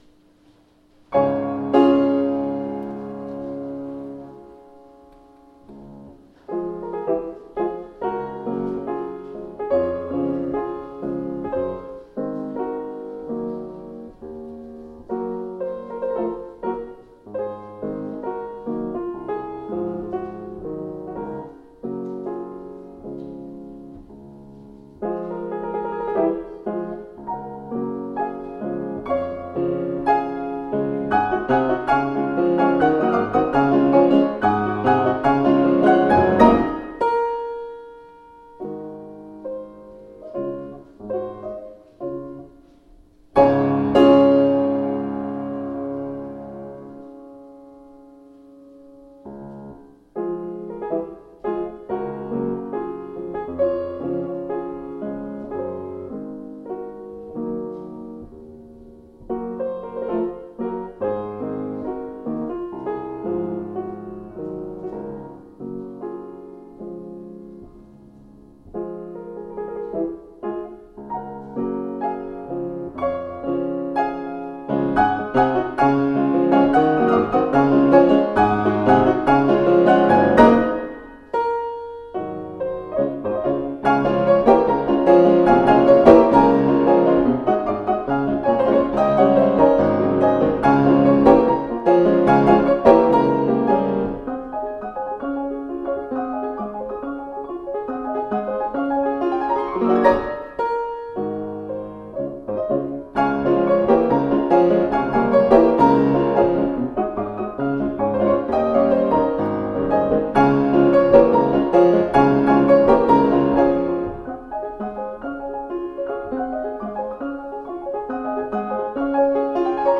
POSTLUDE -  Hungarian Dance No.6 in D-flat Major  - Johannes Brahms
Many contrasting moods and emotions.